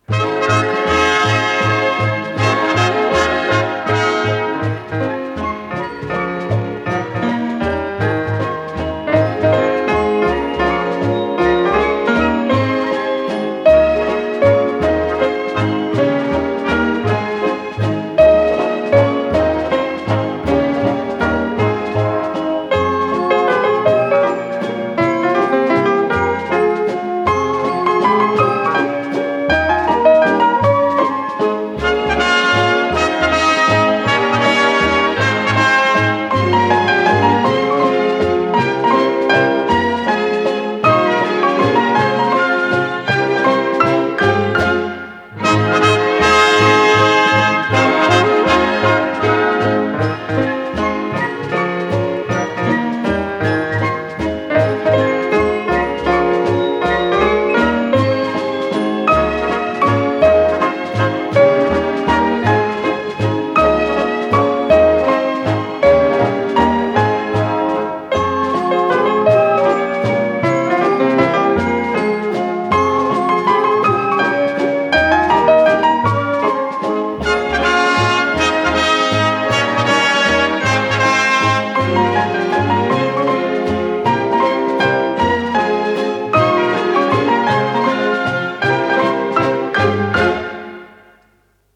ПодзаголовокЗаставка, ля бемоль мажор
ВариантДубль моно